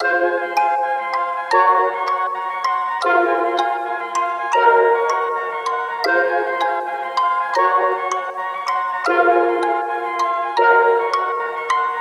Slider (159 BPM – Fm)
UNISON_MELODYLOOP_Slider-159-BPM-Fm.mp3